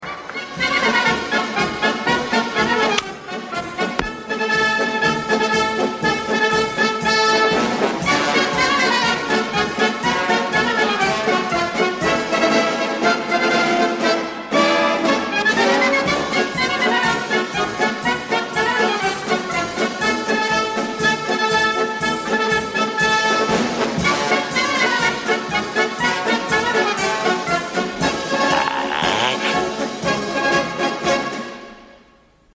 Точно известно лишь то, что мелодия была исполнена итальянским оркестром и записана на итальянской пластинке.